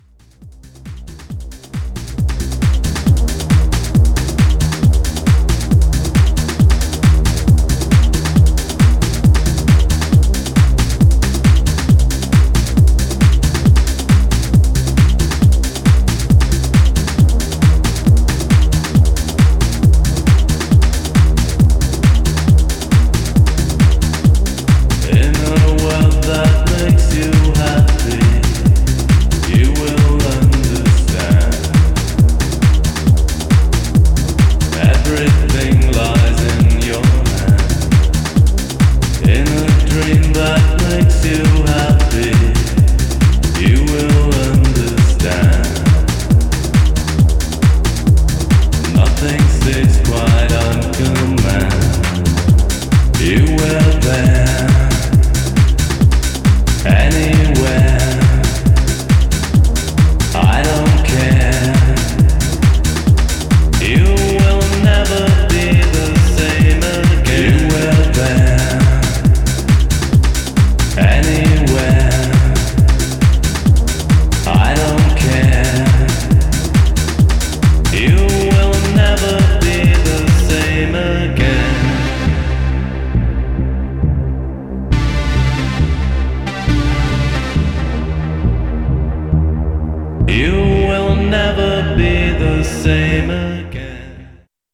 Styl: Progressive, House, Techno, Trance